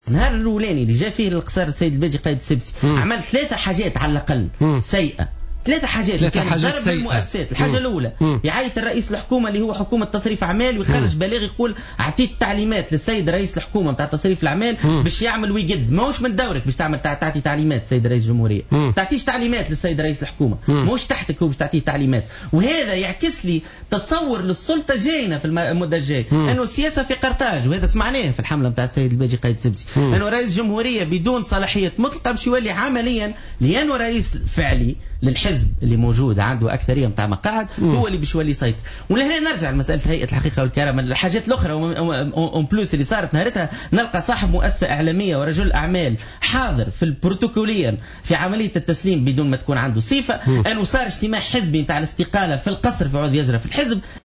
Sur le plateau de Jawhara Fm, le dirigeant du parti le Congrès pour la République (CPR), Tarek Kahlaoui a fustigé les premières actions faites par le président élu Béji Caïd Essebsi, qui selon lui, a d’ores et déjà outrepassé ses prérogatives.